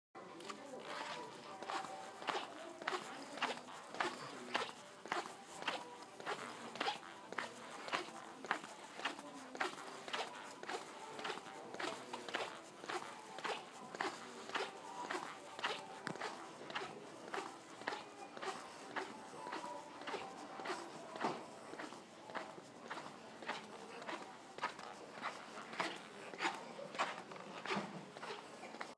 Squeaky Shoes
squeaky-shoes.m4a